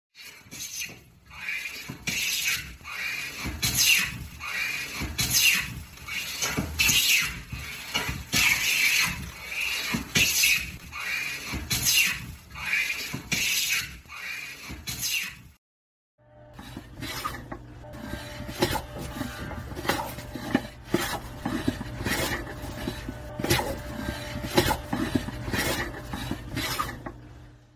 Tổng hợp tiếng Bào gỗ bằng Tay thủ công
Thể loại: Tiếng động
Description: Tổng hợp âm thanh bào gỗ thủ công ngày xưa, tiếng cưa, tiếng bào, tiếng mài nhẵn vang lên mộc mạc, chân thực. Hiệu ứng âm thanh này gợi lại khung cảnh xưởng mộc truyền thống, nơi bàn tay người thợ miệt mài tạo nên nhịp điệu đặc trưng của nghề mộc. Những âm hưởng mài giũa, xước gỗ, chà nhám hòa quyện tự nhiên, mang lại cảm giác hoài cổ, gần gũi.
tong-hop-tieng-bao-go-bang-tay-thu-cong-www_tiengdong_com.mp3